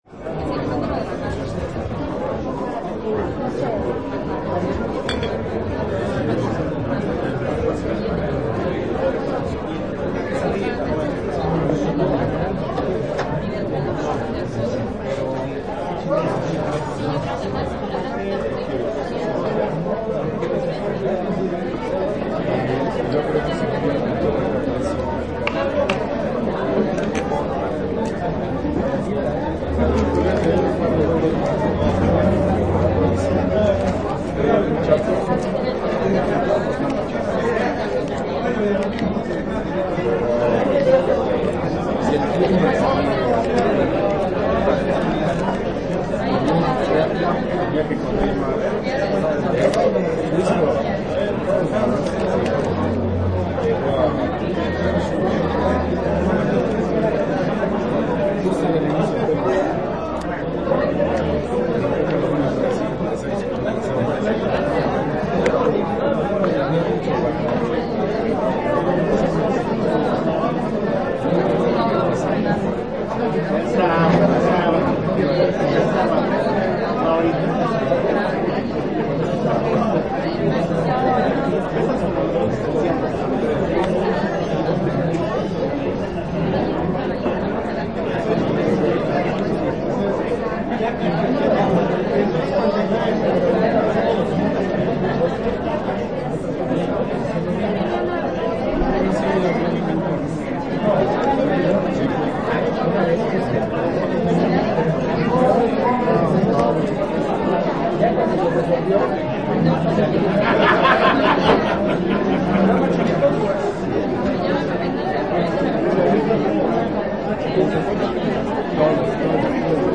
Los invitamos a escuchar uno de los tantos sonidos que el Centro Histórico de la Ciudad de Mexico nos ofrece.
Este es un fragmento de una tarde en el Salón Corona, donde amigos, compañeros de trabajo, parejas y familias se han reunido desde 1928 para tomarse unas cervezas y degustar tortas, tacos, quesadillas, mariscos, chiles rellenos, entre muchos otros antojitos.
Equipo: Minidisc NetMD MD-N707, micrófono de construcción casera (más info)